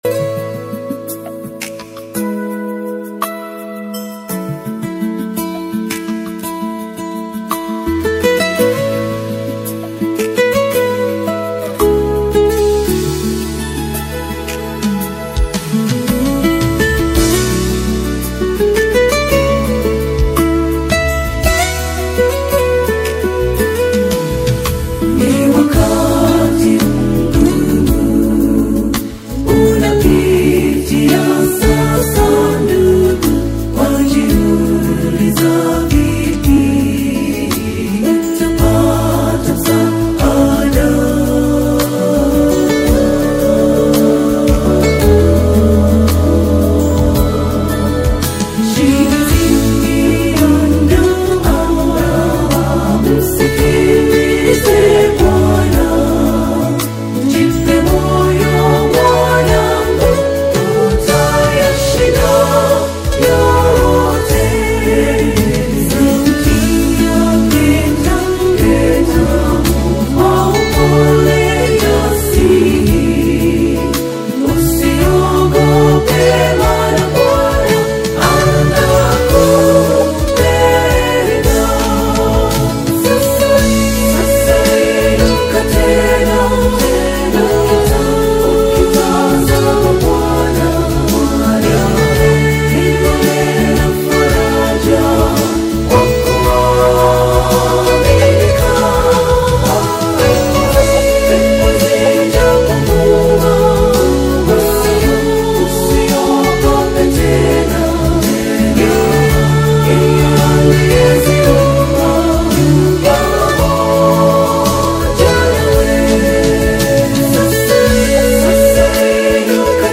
powerful, restorative anthem